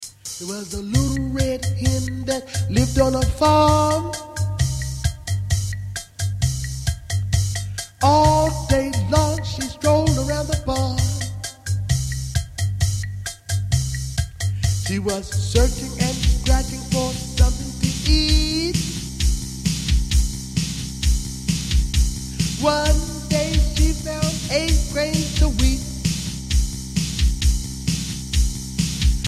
Children's Nursery Rhyme and Sound Clip